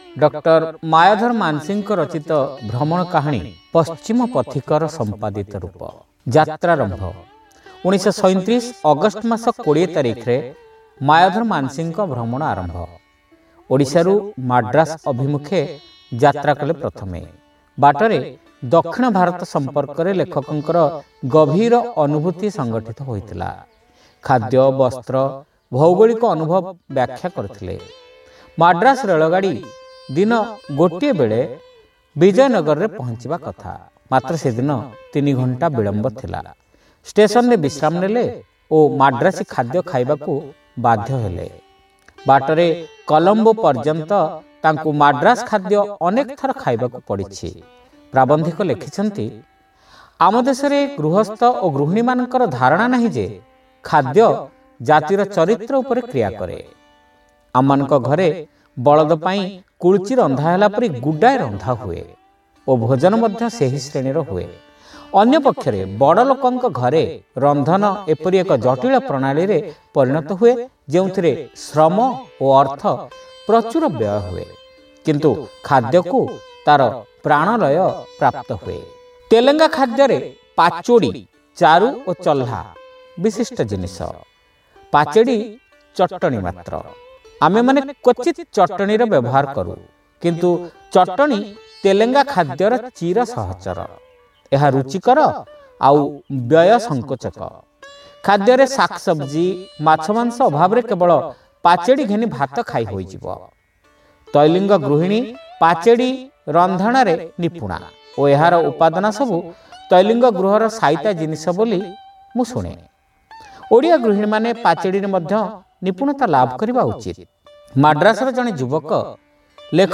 Audio Story : Paschima Pathika ra Sampadita Rupa 1